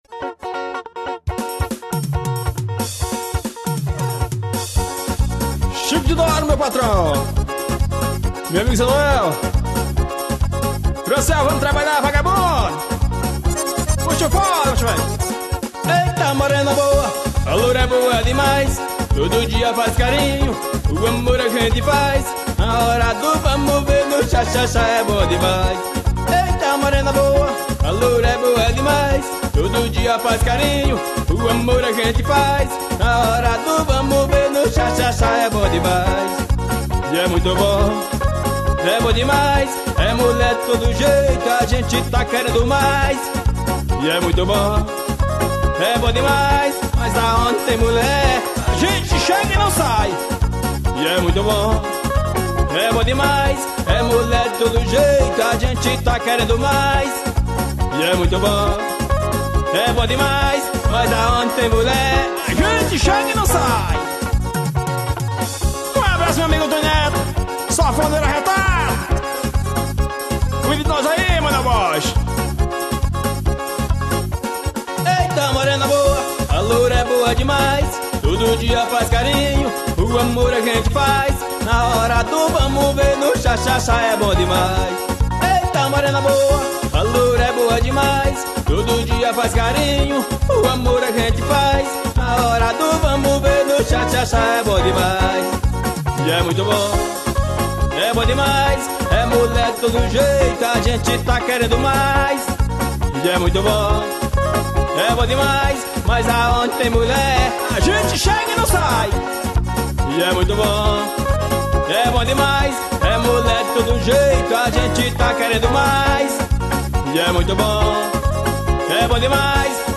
forro quente e arrochado.